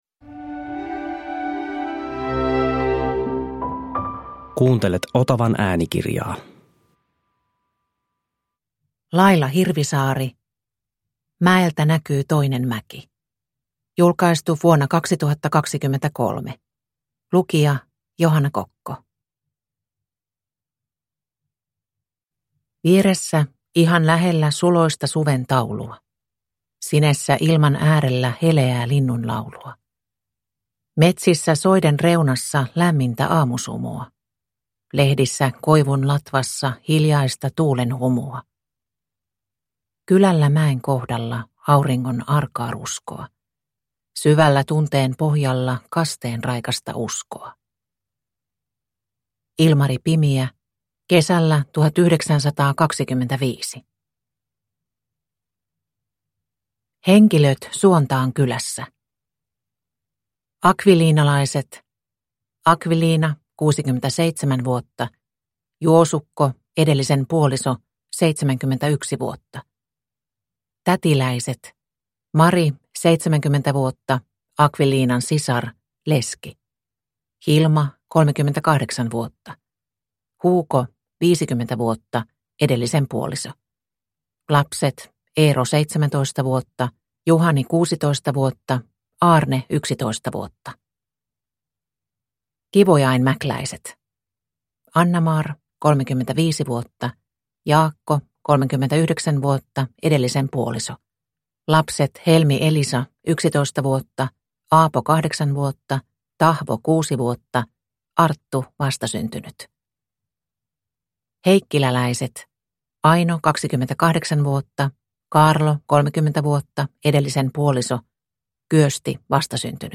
Mäeltä näkyy toinen mäki – Ljudbok – Laddas ner